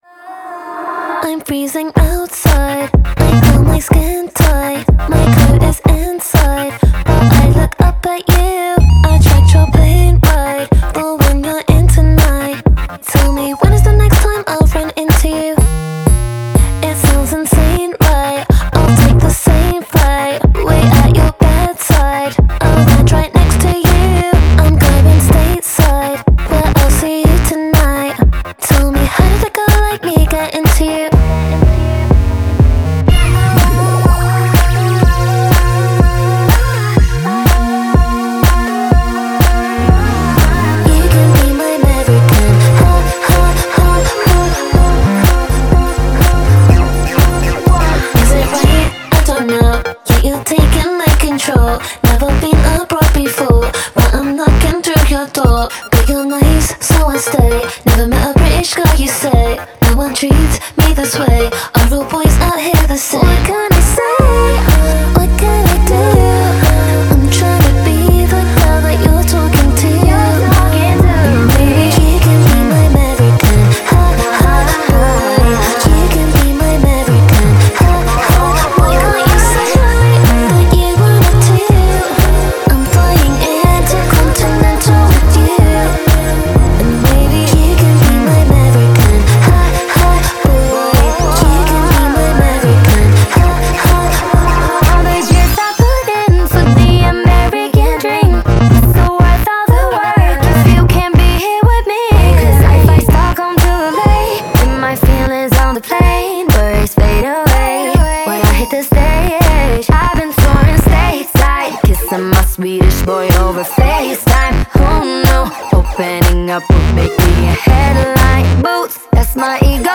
BPM123-123
Audio QualityPerfect (High Quality)
Pop song for StepMania, ITGmania, Project Outfox
Full Length Song (not arcade length cut)